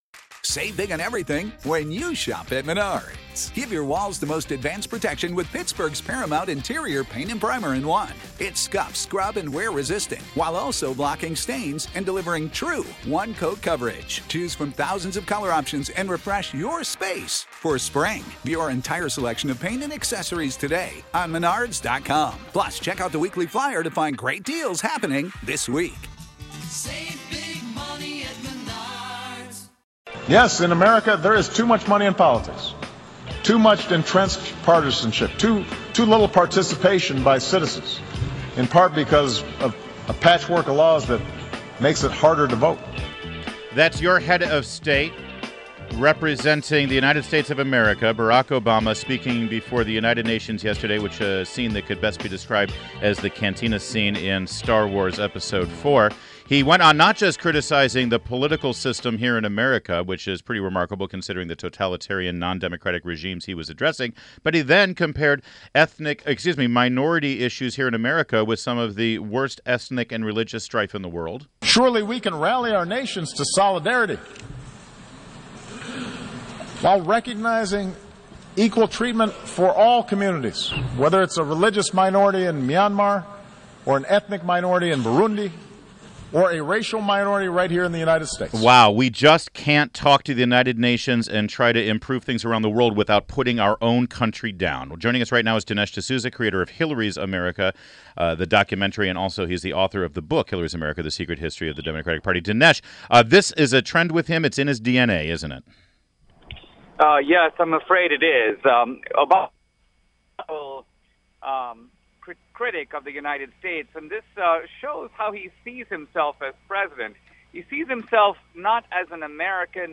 WMAL Interview - DINESH D'SOUZA - 09.21.16